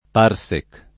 [ p # r S ek ]